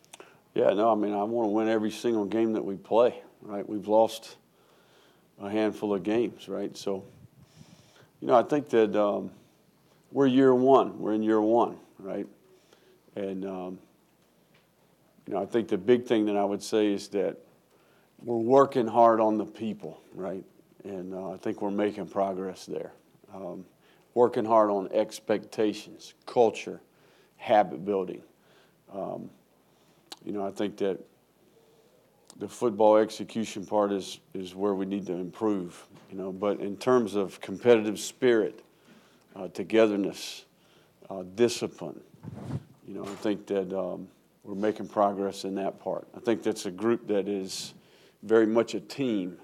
Florida head coach Billy Napier began the postgame press conference by stating that he respects the fight from his guys against LSU.